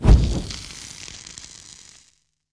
hand mining
erupt.wav